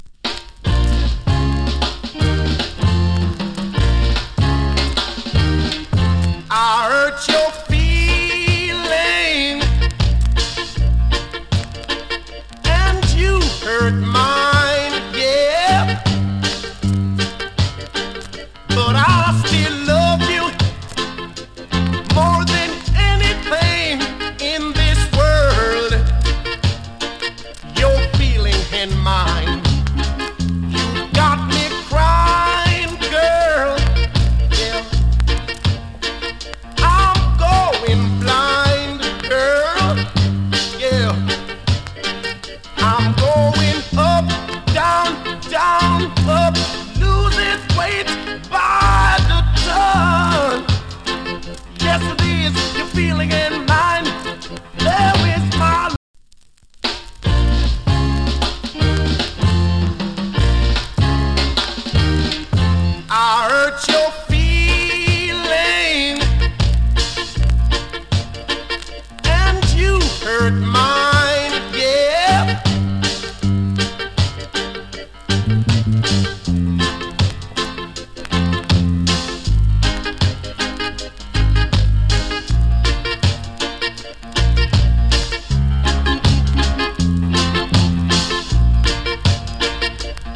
チリ、パチノイズ少々有り。
マイナー調の NICE EARLY REGGAE !